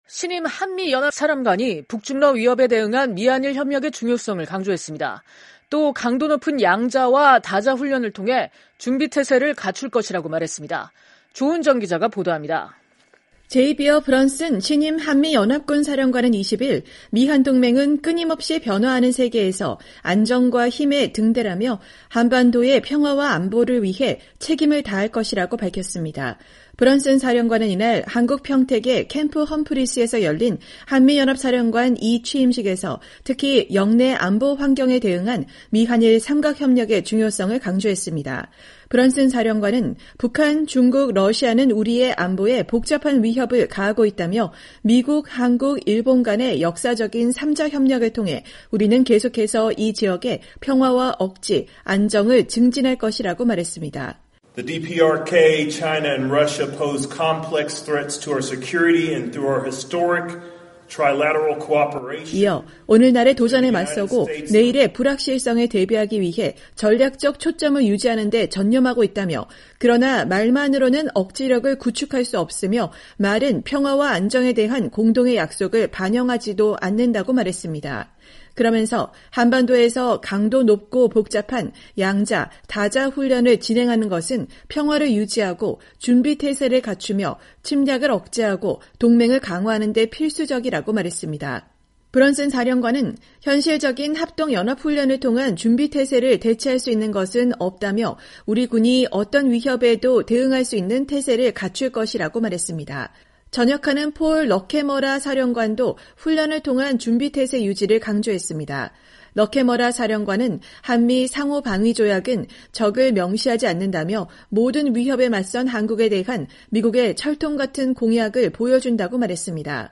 2024년 12월 20일 한국 평택 캠프 험프리스에서 열린 한미연합사령관 이취임식에서 제이비어 브런슨 신임 사령관이 연설하고 있다.